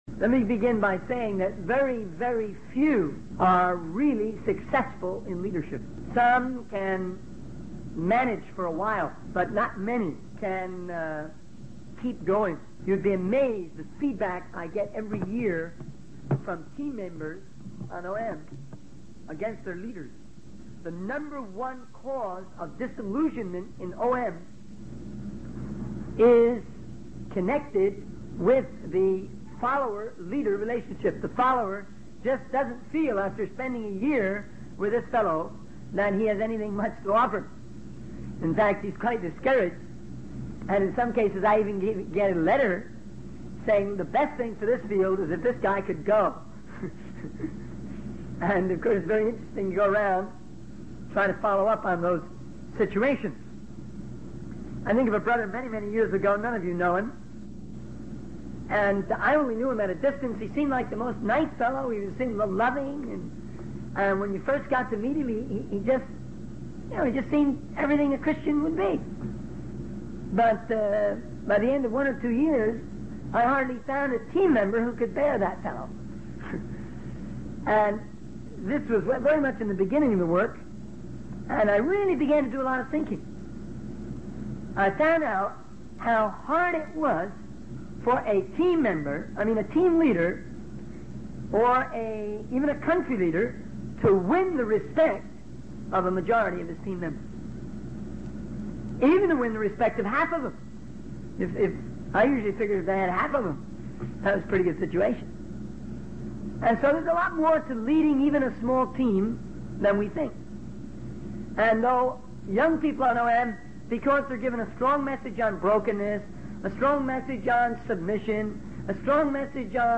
In this sermon, the speaker emphasizes the importance of believing in people and understanding them as a leader.